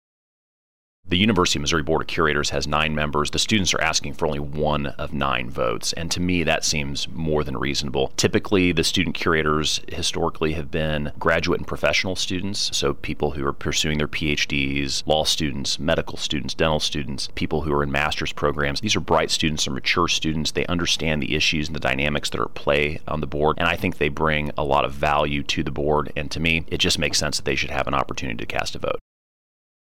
2. Senator Luetkemeyer adds Senate Bill 265 would replace the student representative on the board of curators of the University of Missouri with a student curator.